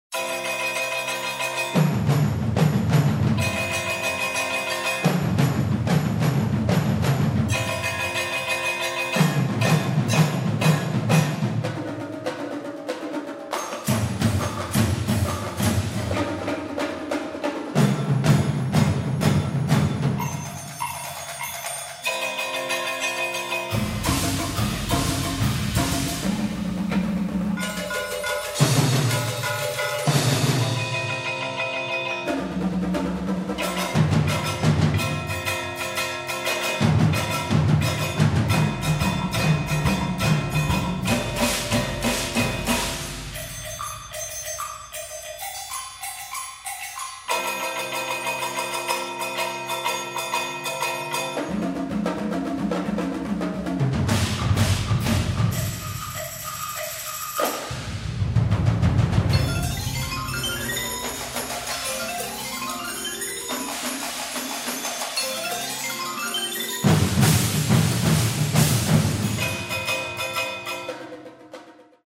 modern classical music for percussion
for eight percussionists
opening section